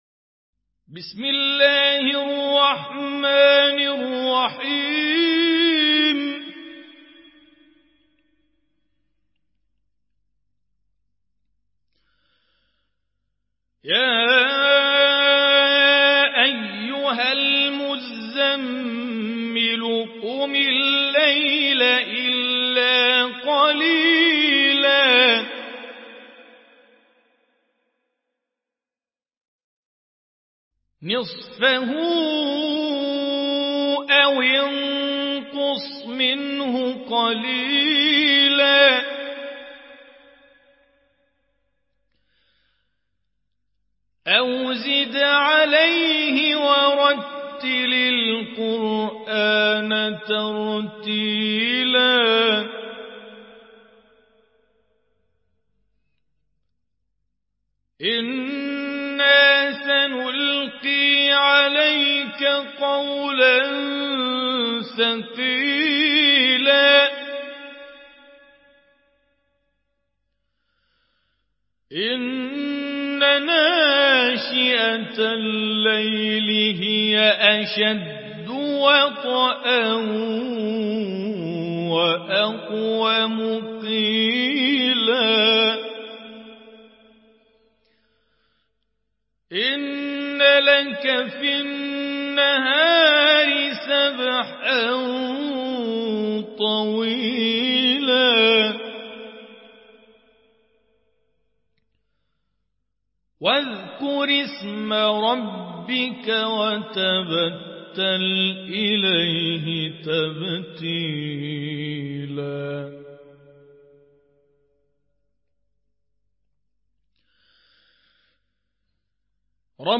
برواية حفص عن عاصم
مرتل